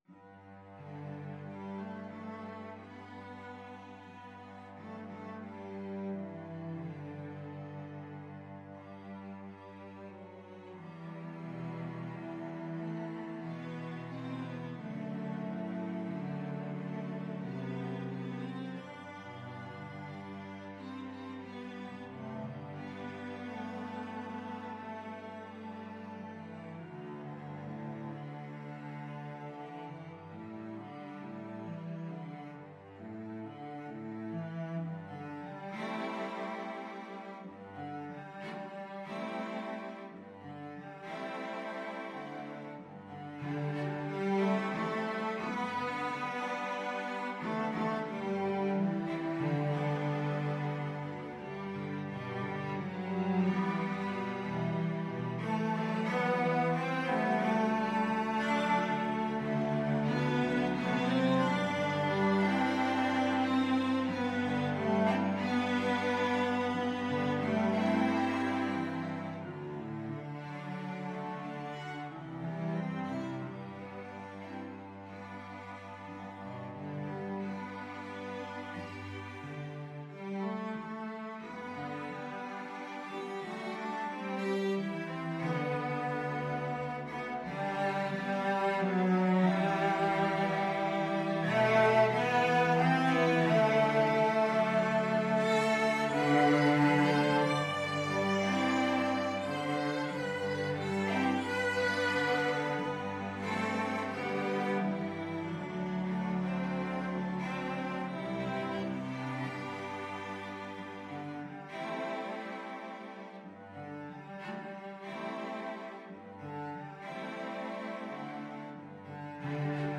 Cello 1Cello 2Cello 3Cello 4
4/4 (View more 4/4 Music)
Andante cantabile = c. 90